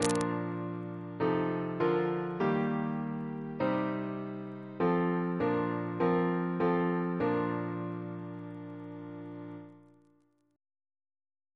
CCP: Chant sampler
Single chant in B♭ Composer: William Hayes (1707-1777) Reference psalters: PP/SNCB: 22